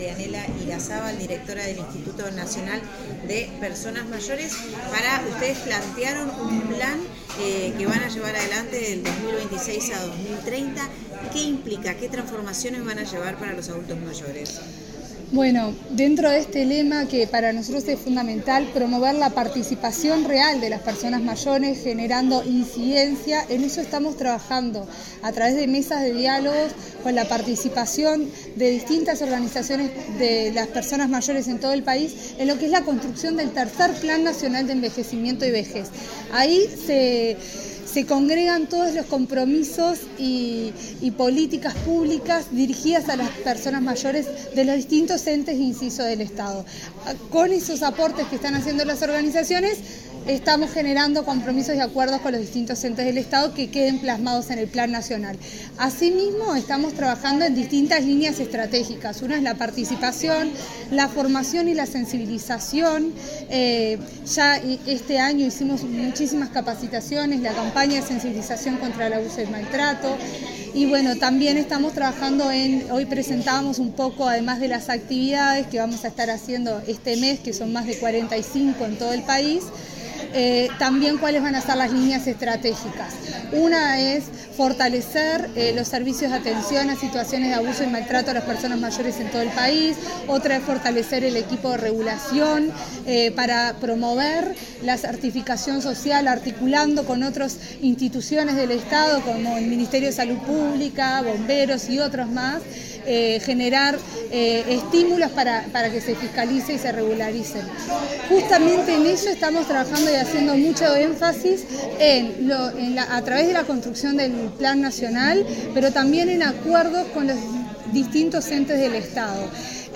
Declaraciones de la directora de Inmujeres, Marianela Larzábal